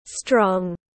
Khỏe mạnh tiếng anh gọi là strong, phiên âm tiếng anh đọc là /strɒŋ/ .
Strong /strɒŋ/